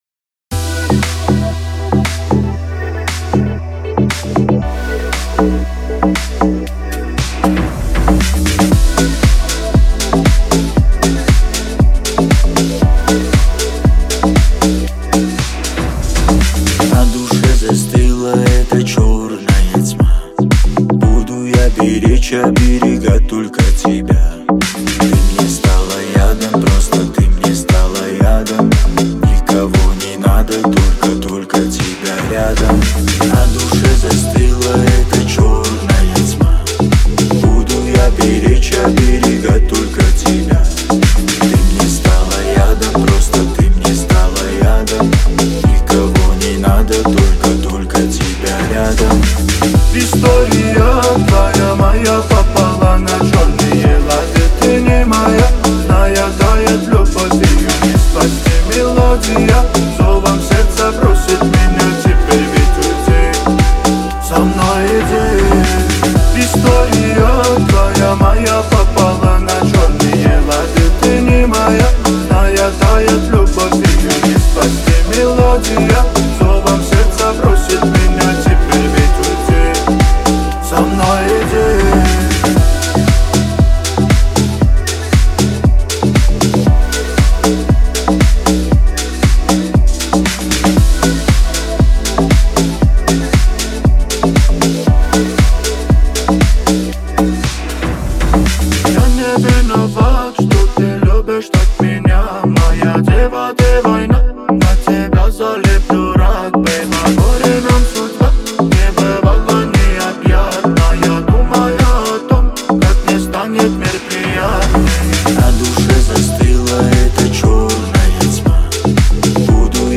это мощная композиция в жанре хип-хоп